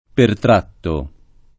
pertrattare v.; pertratto [ pertr # tto ]